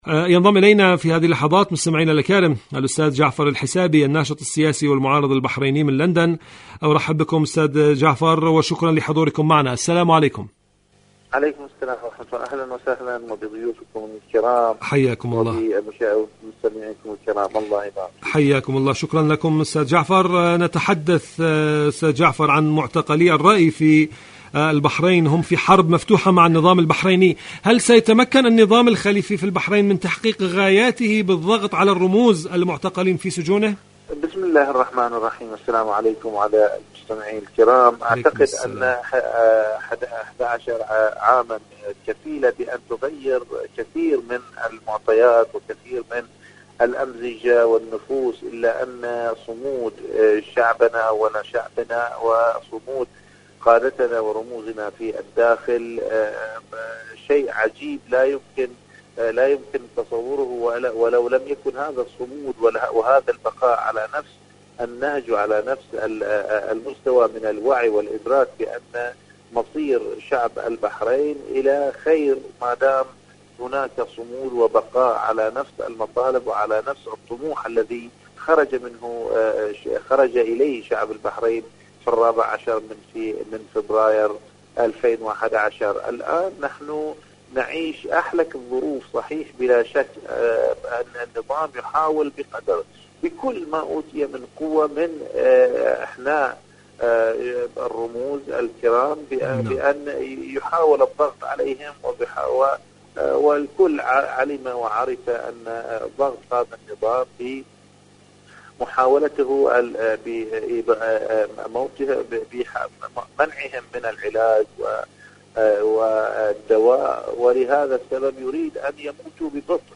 إذاعة طهران-بحرين الصمود: مقابلة إذاعية